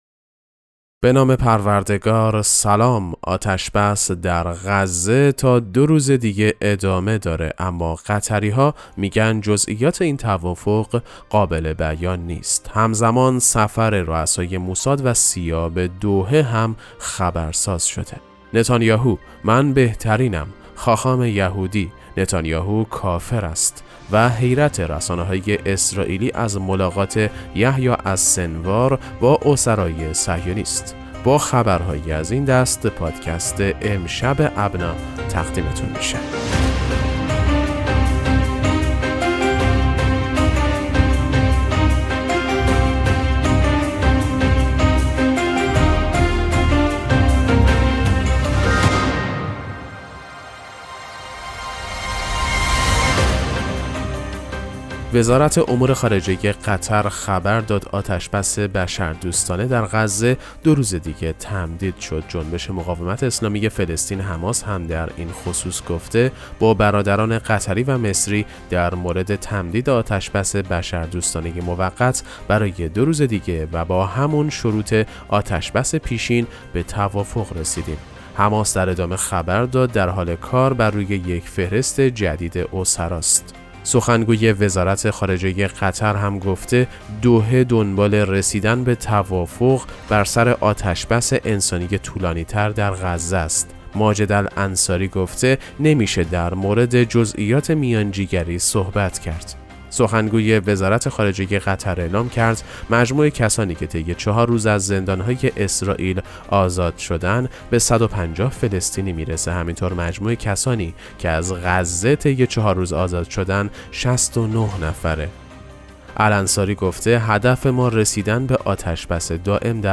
پادکست مهم‌ترین اخبار ابنا فارسی ــ 7 آذر 1402